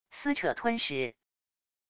撕扯吞食wav音频